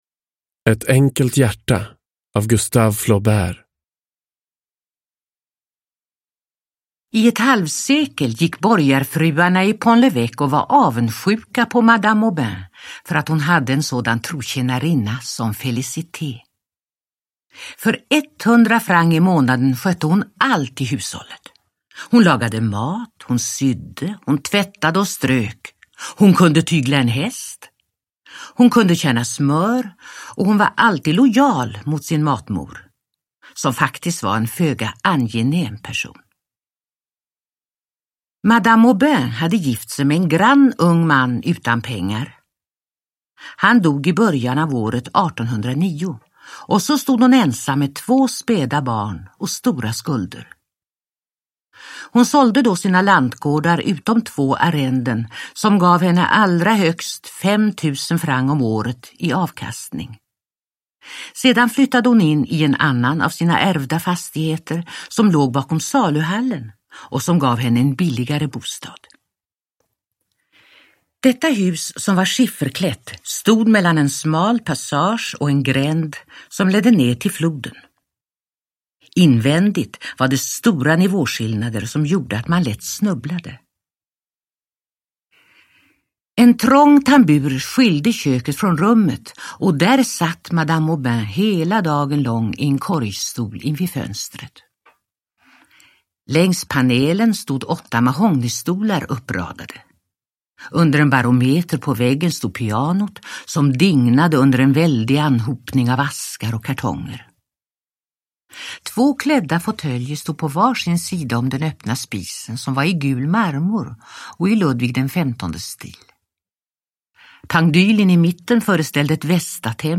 Ett enkelt hjärta – Ljudbok – Laddas ner
Uppläsare: